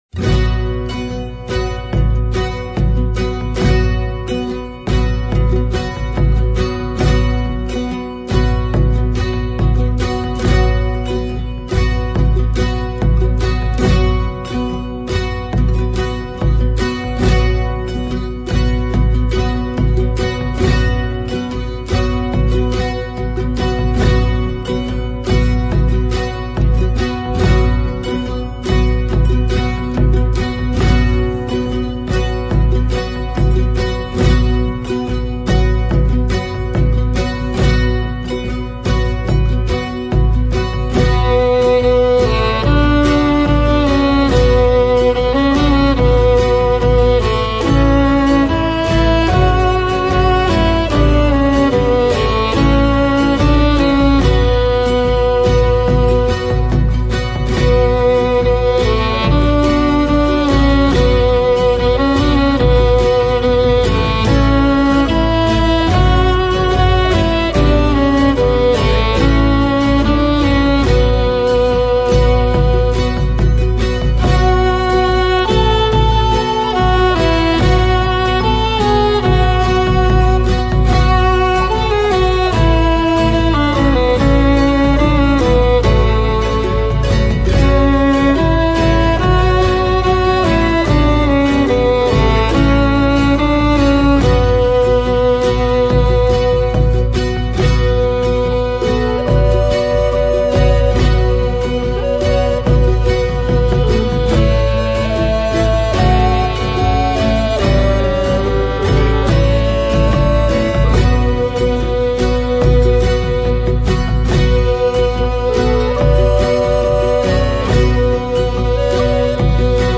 Mit duftiger Leichtigkeit und beschwingter Spielfreude